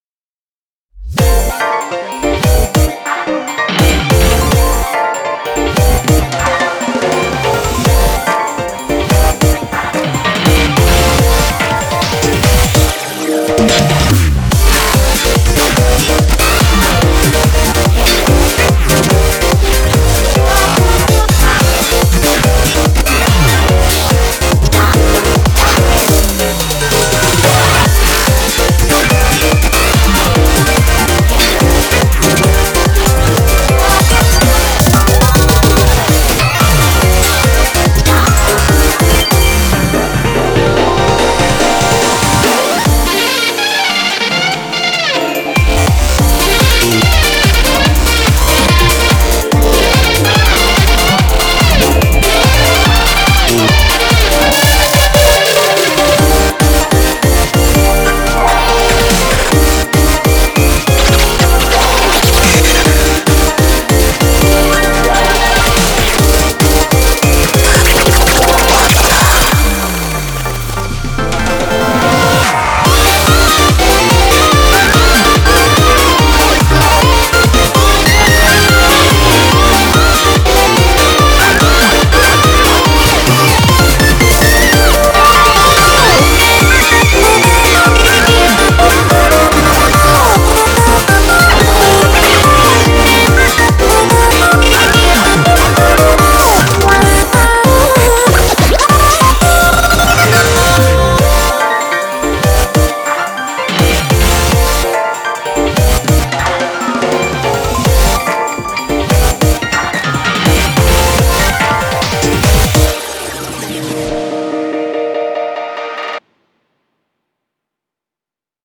BPM144
Audio QualityPerfect (High Quality)
Genre; JAZZY-ELECTRO.